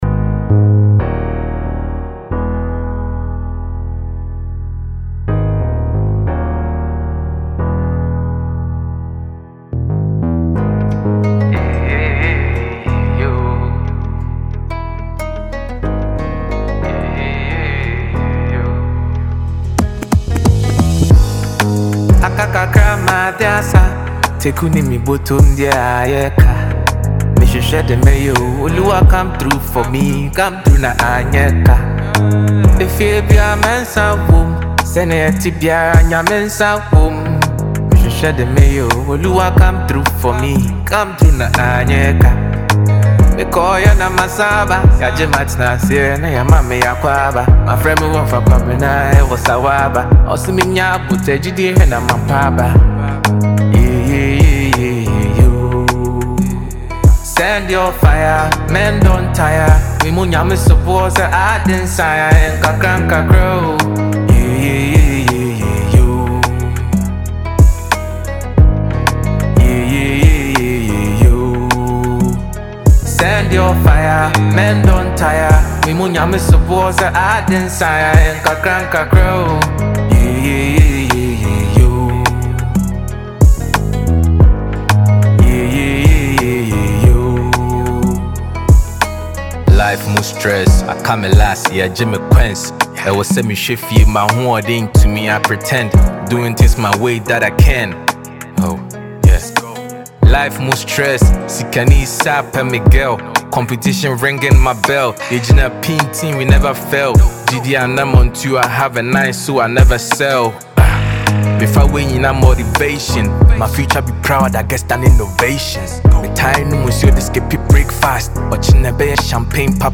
a talented Ghanaian asakaa rapper